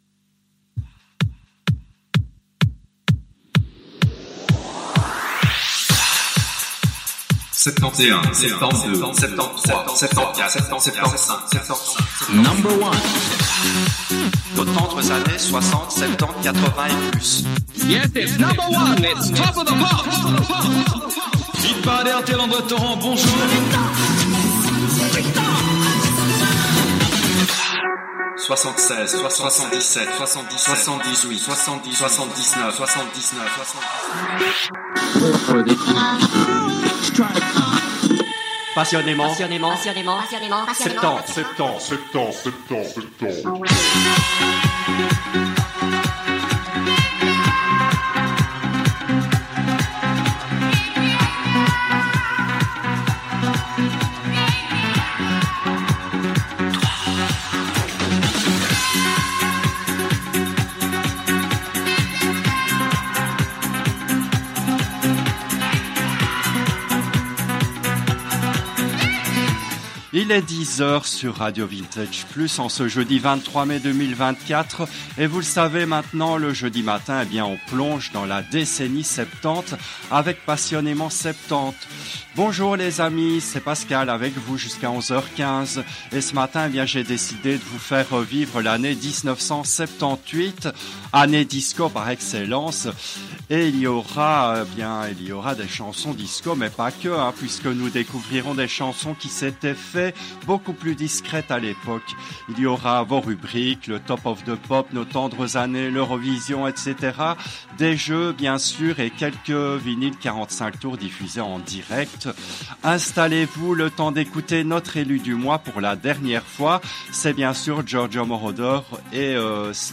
Cette émission a été diffusée en direct le jeudi 23 mai 2024 à 10h depuis les studios belges de RADIO RV+.